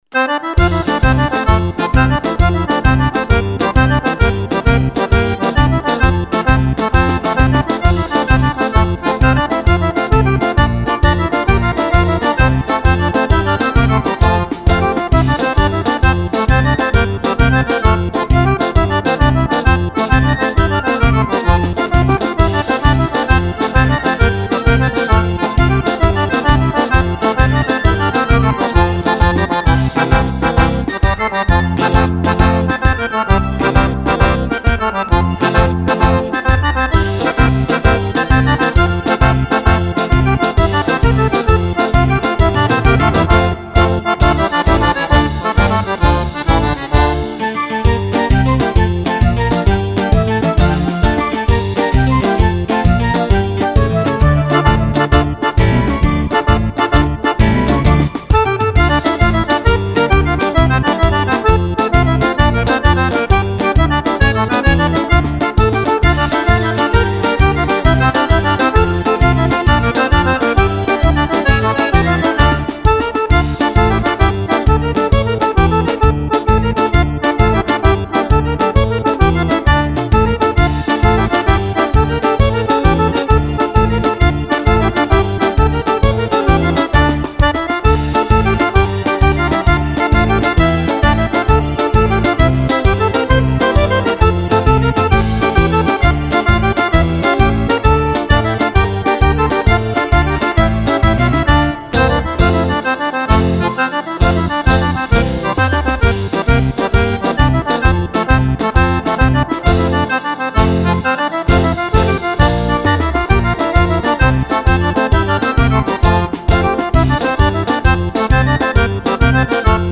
Tarantella
10 BALLABILI PER FISARMONICA E ORCHESTRA